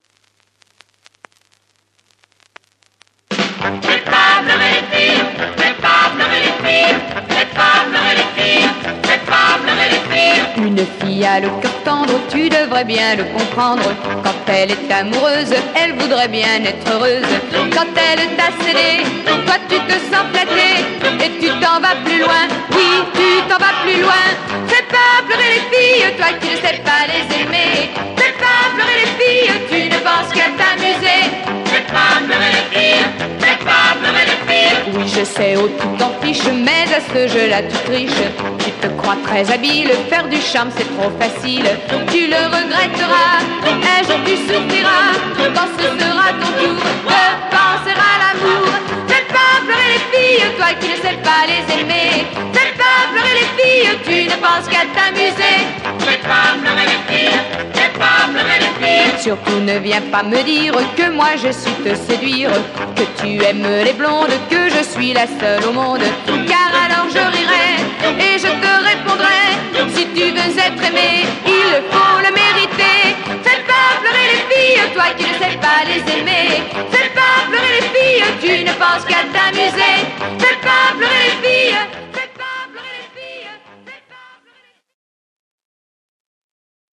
French female Yéyé Soul